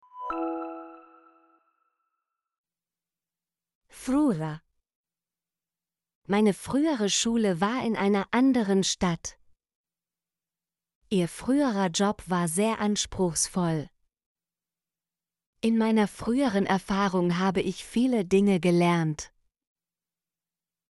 frühere - Example Sentences & Pronunciation, German Frequency List